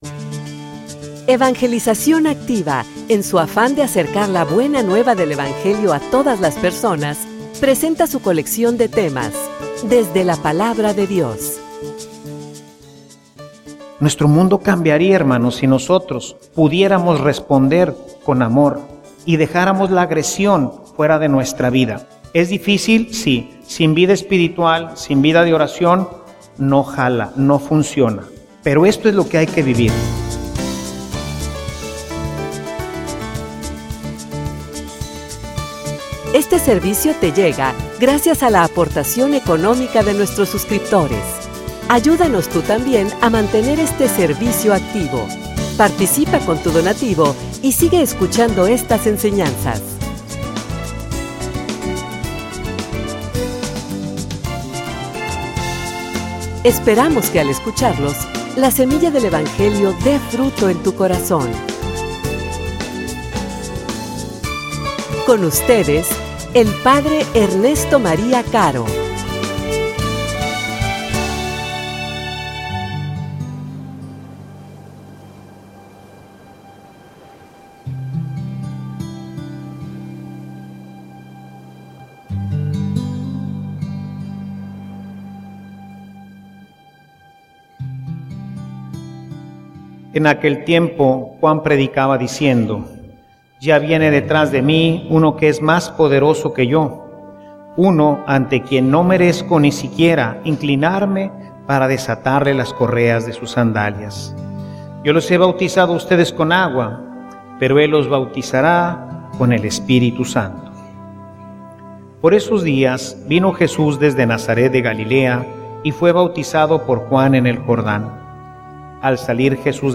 homilia_Bautismo_Palabra_Vida.mp3